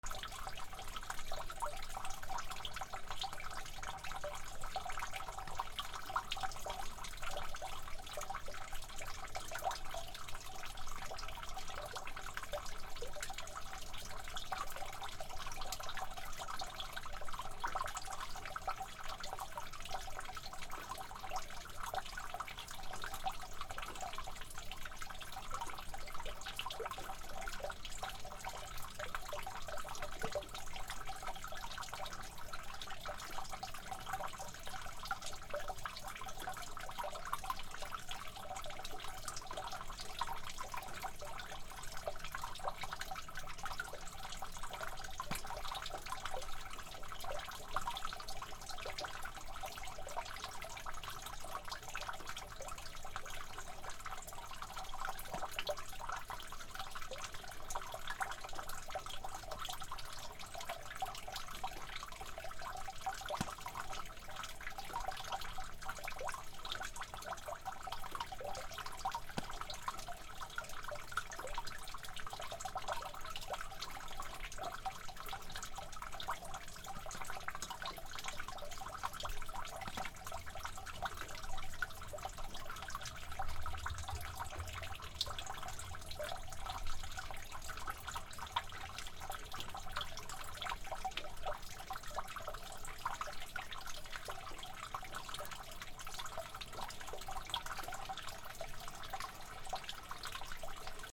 雨 側溝にチョロチョロ落ちる雨
/ B｜環境音(自然) / B-15 ｜水の流れ